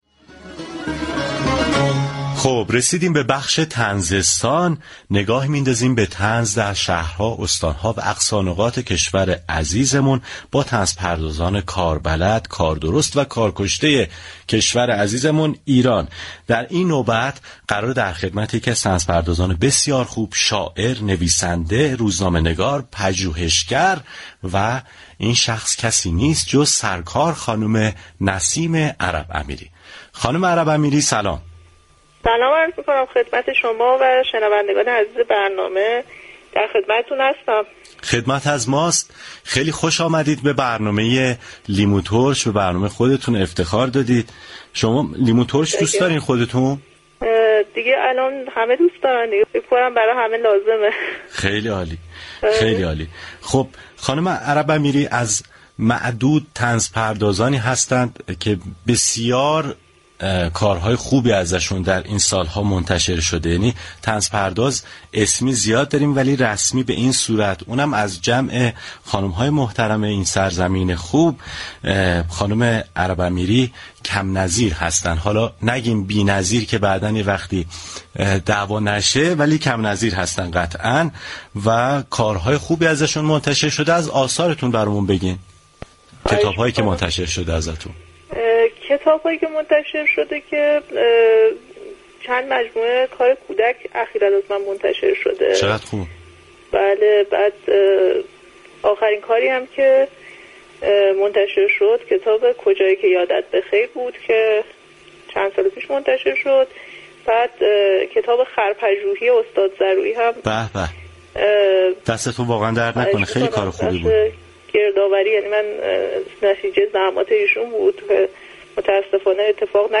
شنونده گفتگوی برنامه لیموترش با